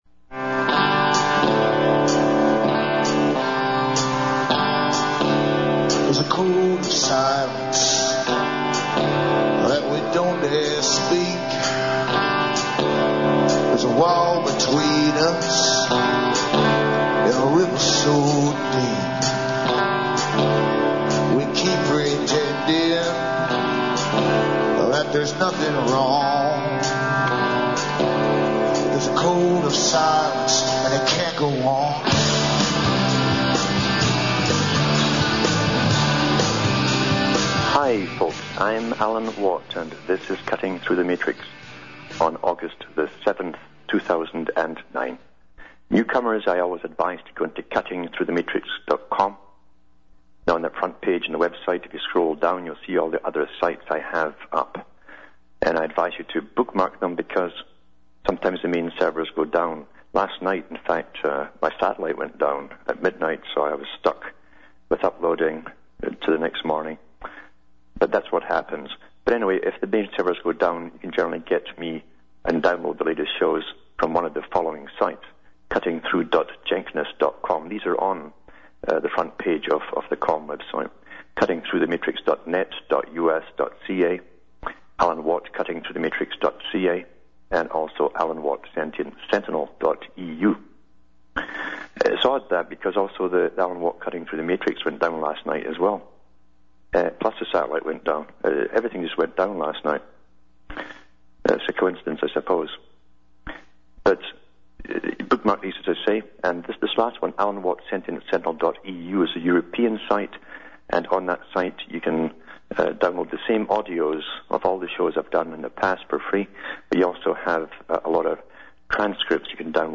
Live on RBN